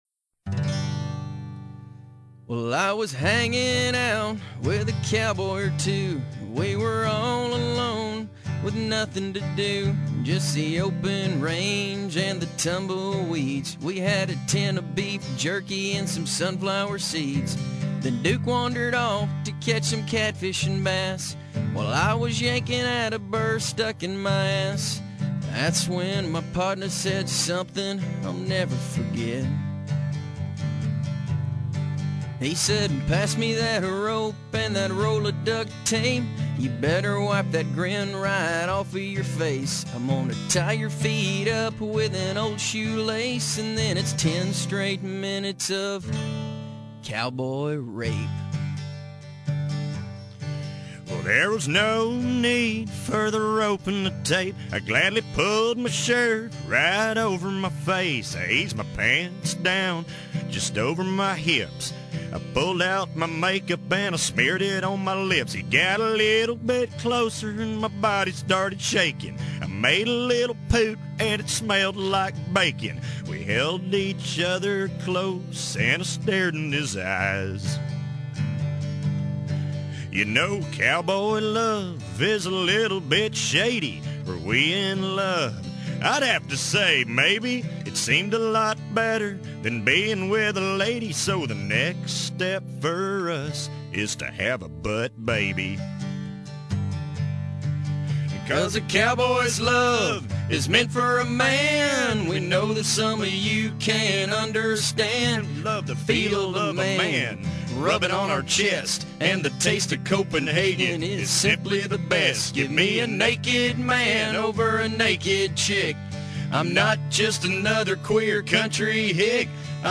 love ballads